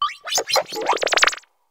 glimmet_ambient.ogg